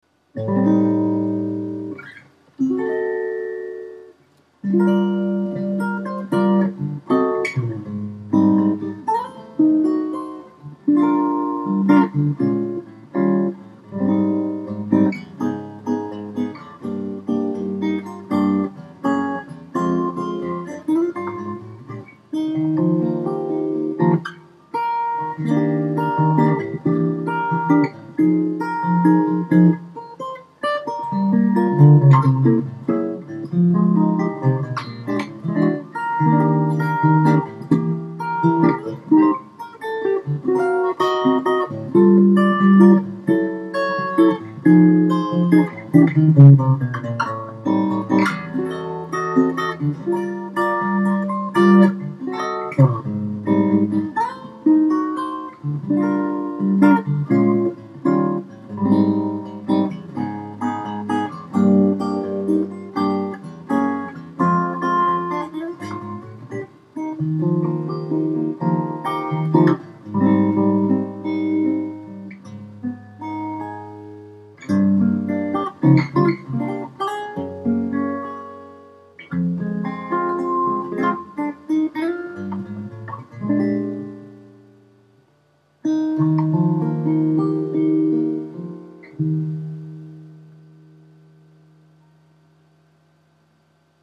5. マグネットマイク＋コンタクトマイク
4.同様、やわらかいサウンドですが、箱鳴り（ボディー内の空気バランス）に違いがあります。
コンタクトマイクの弱点でもある単発での音量不足や、ボンボンした低音の響きがブレンドによって巧く緩和される部分は、案外盲点ではないでしょうか。